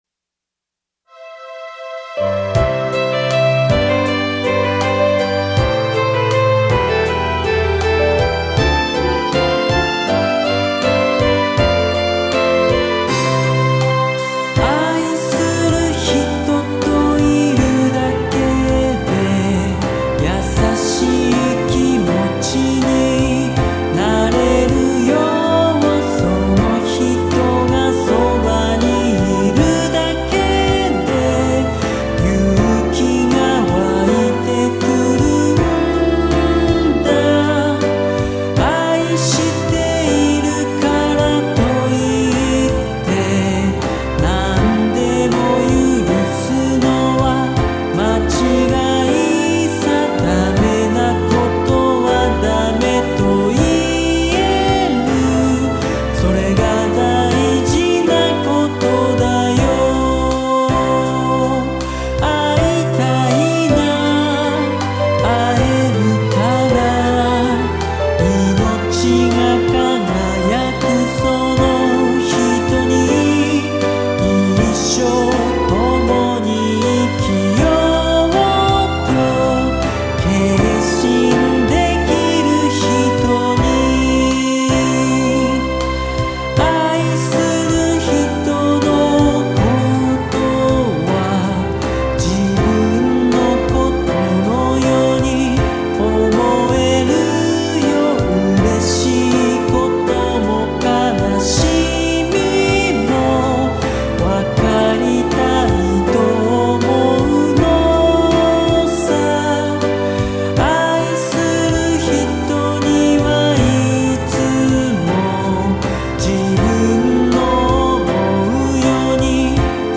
やさしい歌詞とメロディが心に残ります。